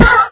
sounds / mob / horse / hit1.ogg
hit1.ogg